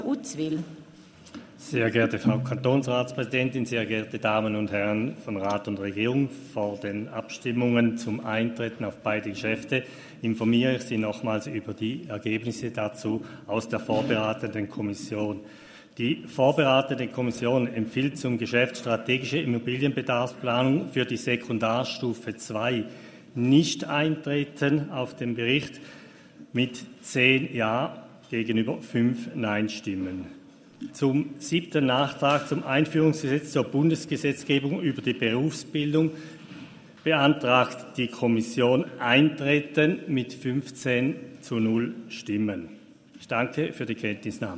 Session des Kantonsrates vom 18. bis 20. September 2023, Herbstsession